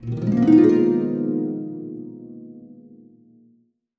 Magical Harp (7).wav